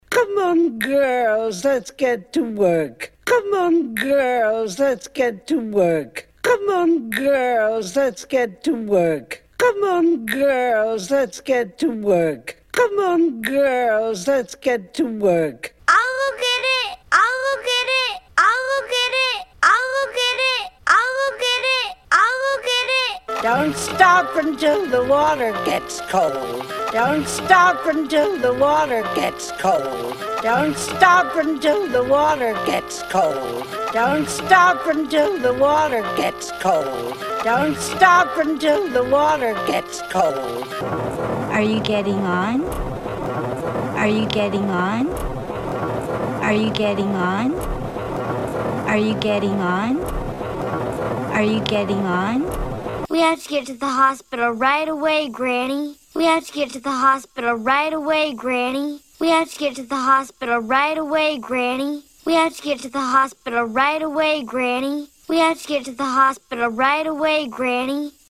連続ループさせた音声であり
【スローループ音声】
音声のスピードをゆっくり再生して
ループさせたものですが
不自然にならない絶妙な速度を研究しました。
1-1 get loop slow.mp3